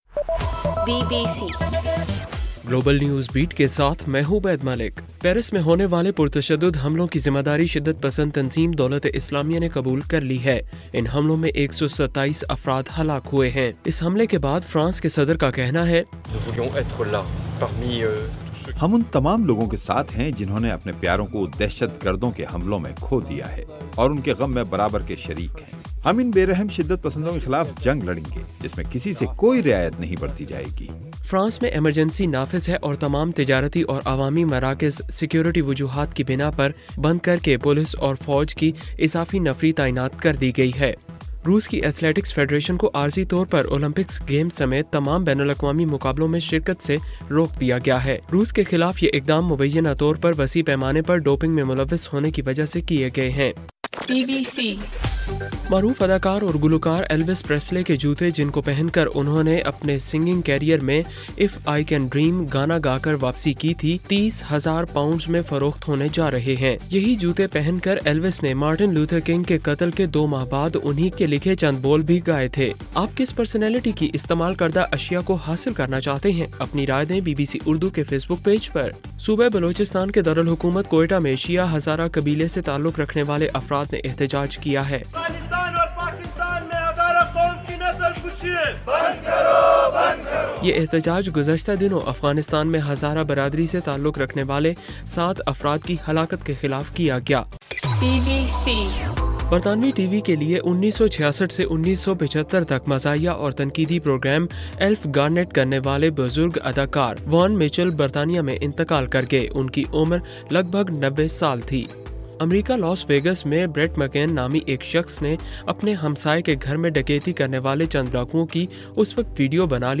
نومبر 14: رات 10 بجے کا گلوبل نیوز بیٹ بُلیٹن